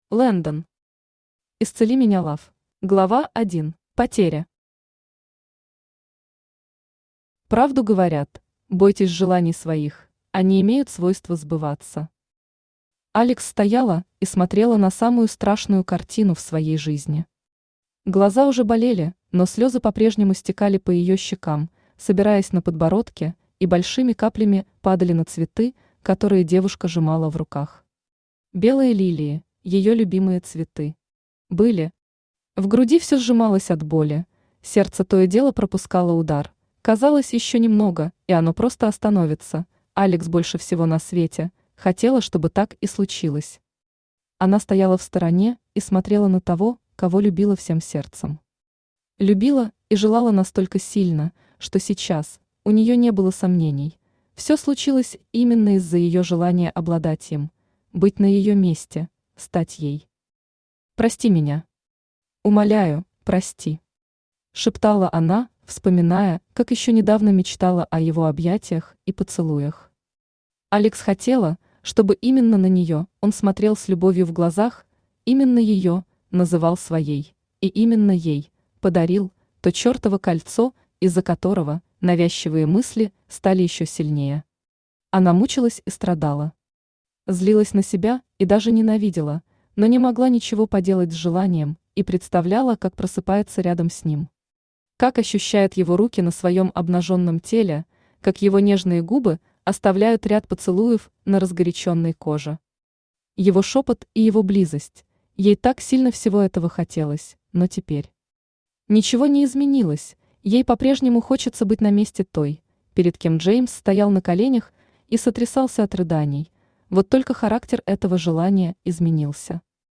Аудиокнига Исцели меня Love | Библиотека аудиокниг
Aудиокнига Исцели меня Love Автор Landen Читает аудиокнигу Авточтец ЛитРес.